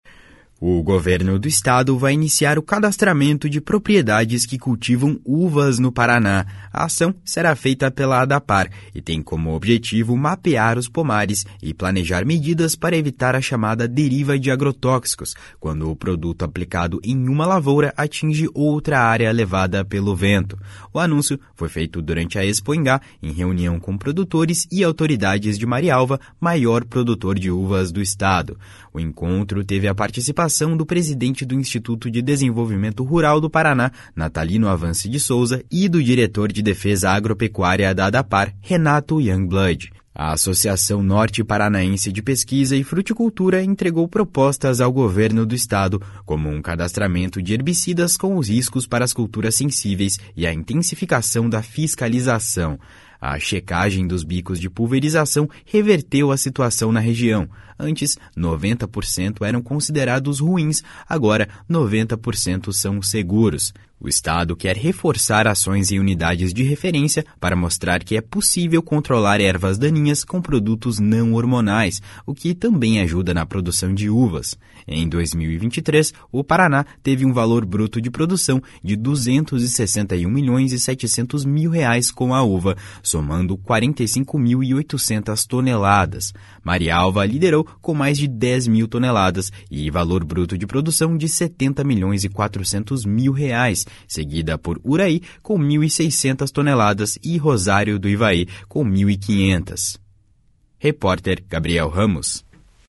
As informações estão na reportagem